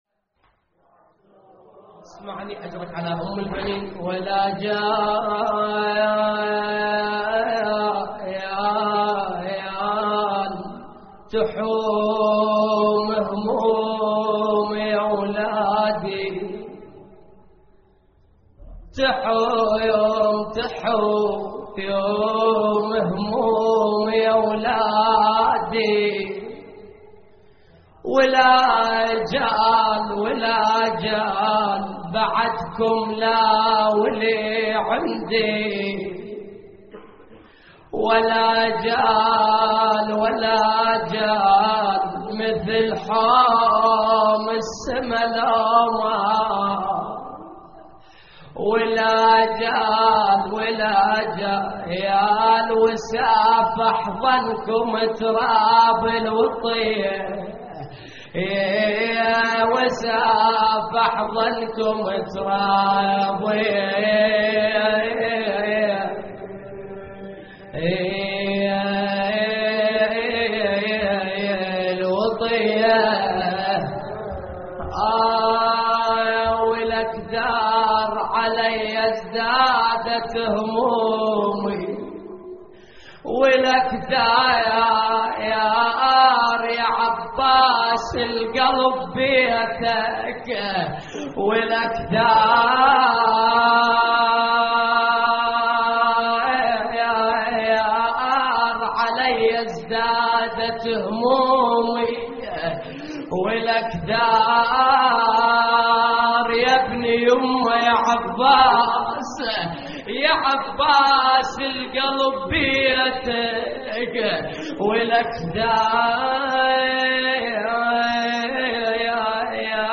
ولاجال تحوم همومي يولادي (نعي)
اللطميات الحسينية